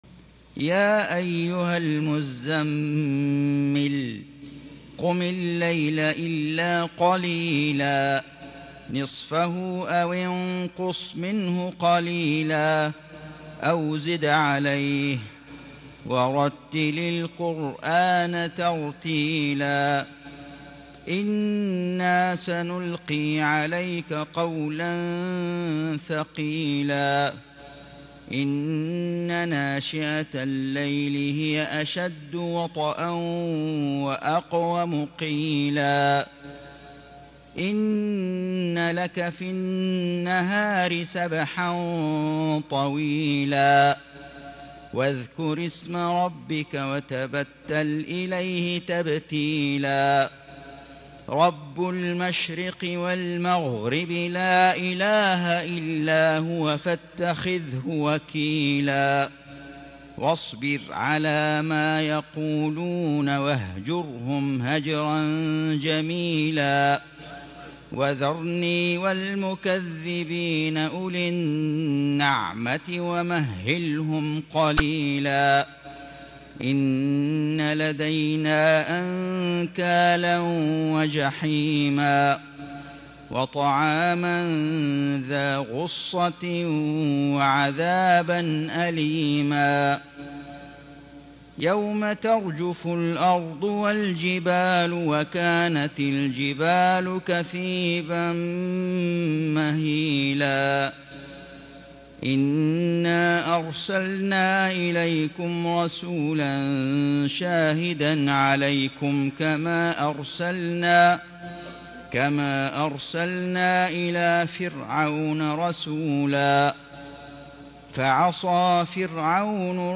سورة المزمل > السور المكتملة للشيخ فيصل غزاوي من الحرم المكي 🕋 > السور المكتملة 🕋 > المزيد - تلاوات الحرمين